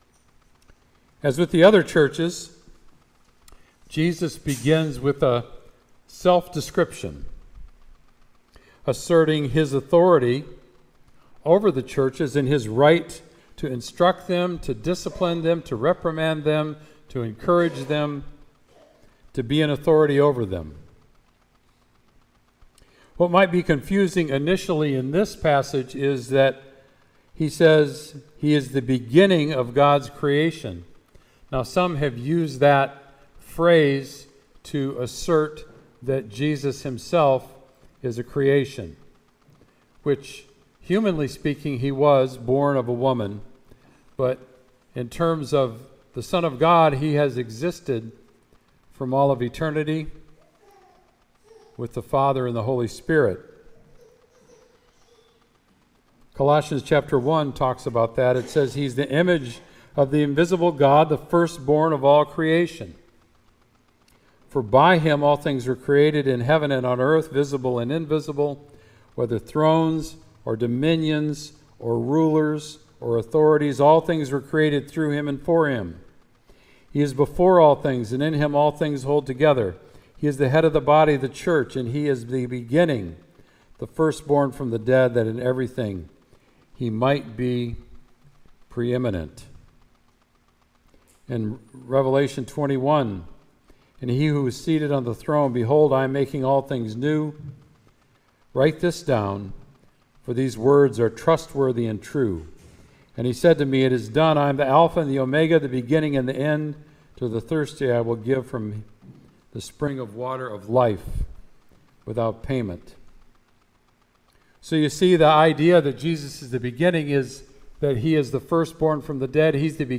Sermon “Be Zealous”